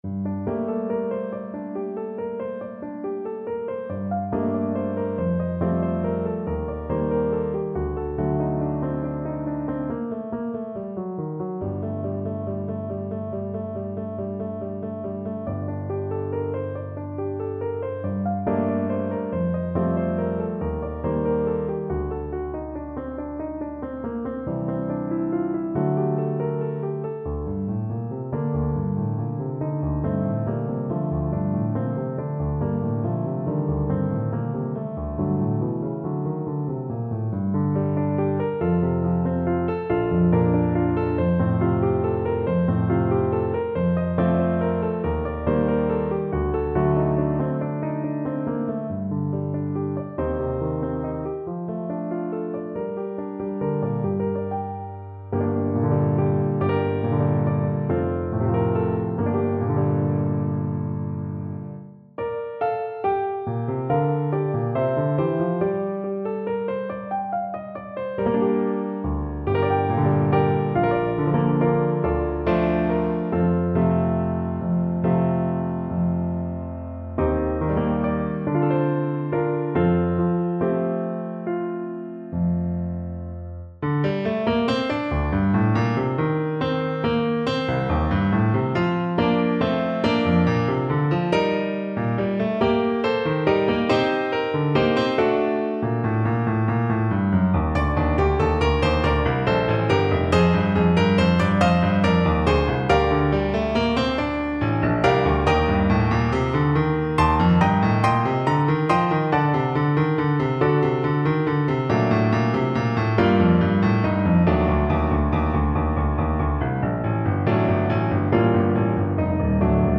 A haunting arrangement of this famous British folk melody.
3/4 (View more 3/4 Music)
Fast, flowing =c.140
Traditional (View more Traditional Clarinet Music)